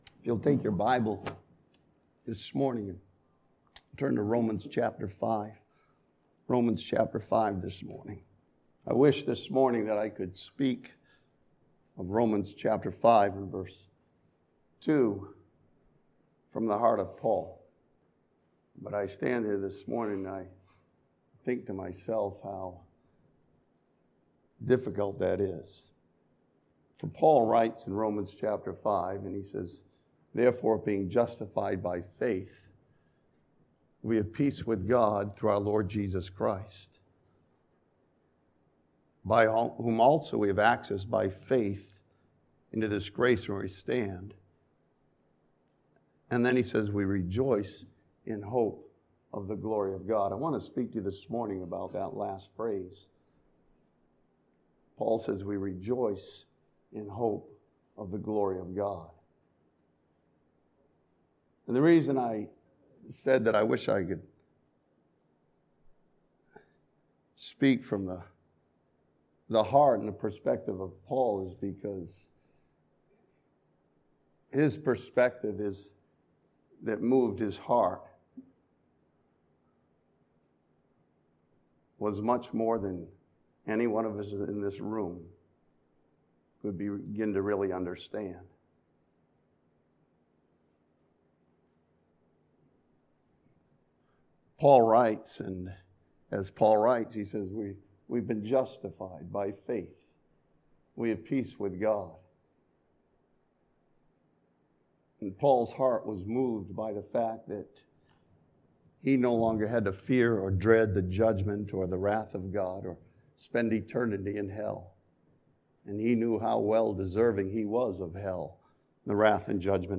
This sermon from Romans chapter five challenges believers to continue on rejoicing in hope of the glory of God.